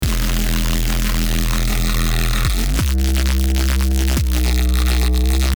❇ Ready for BASS HOUSE, TRAP, UKG, DRUM & BASS, DUBSTEP and MORE!
RS - Earthquake [Emin] 174BPM
RS-Earthquake-Emin-174BPM.mp3